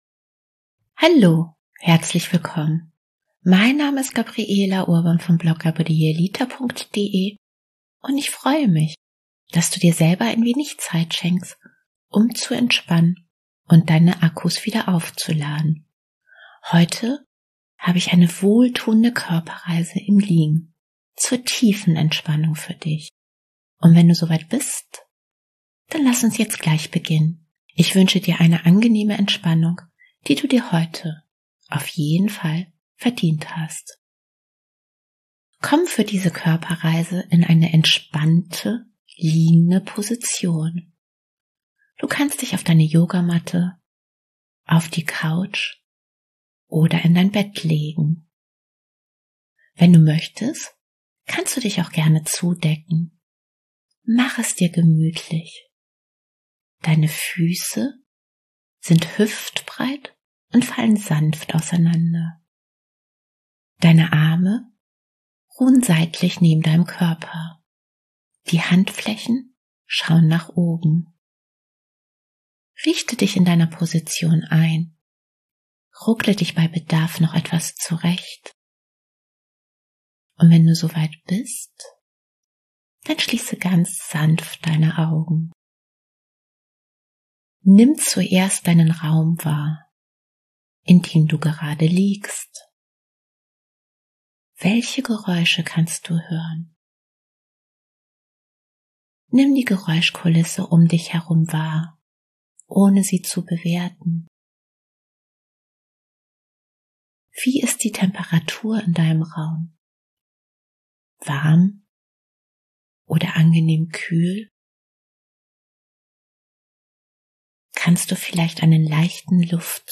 Dann ist die heutige Entspannungsmeditation ohne Musik goldrichtig für dich!